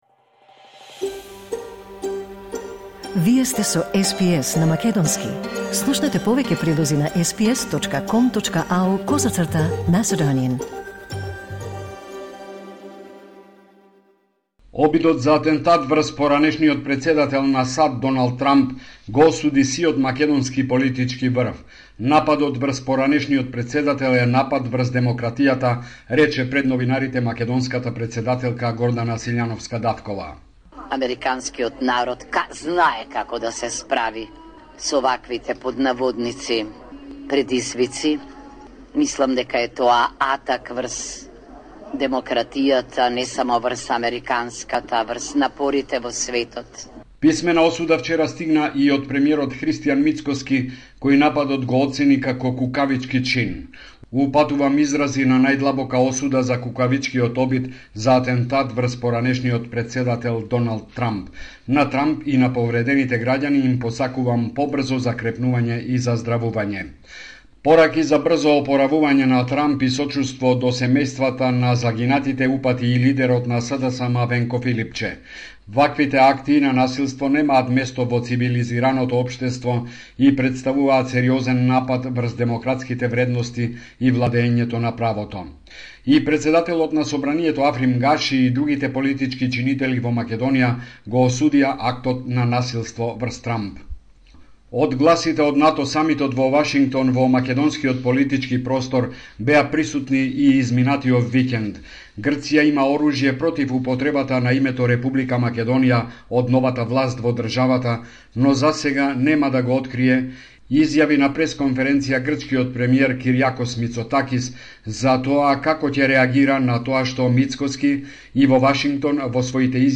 Homeland Report in Macedonian 15 July 2024